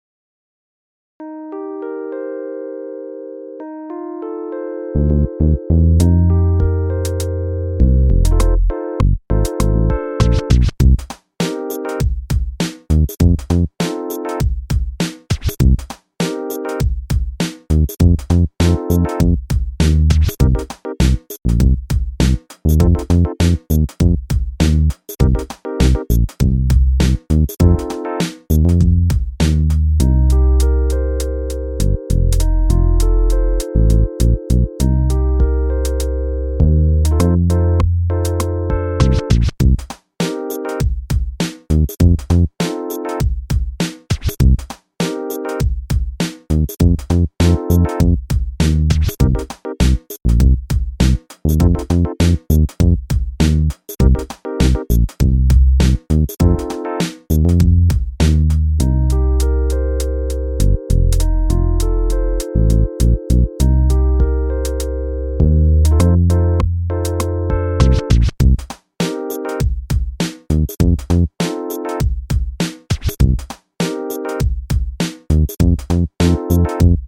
ASSAIG CASTANYADA 2N
I pels més atrevits, la cançó karaoke!!!
Rap-de-la-castanya-sense-veu.mp3